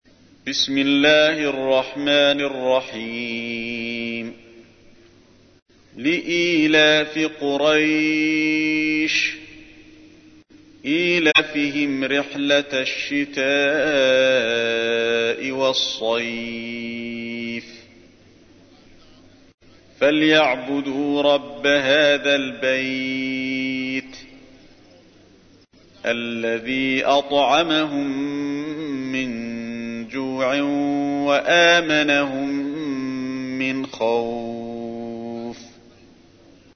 تحميل : 106. سورة قريش / القارئ علي الحذيفي / القرآن الكريم / موقع يا حسين